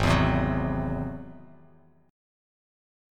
G#+7 chord